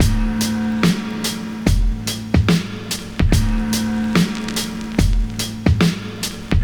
• 72 Bpm 2000s Breakbeat D Key.wav
Free breakbeat - kick tuned to the D note. Loudest frequency: 1240Hz